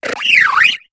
Cri de Déflaisan dans Pokémon HOME.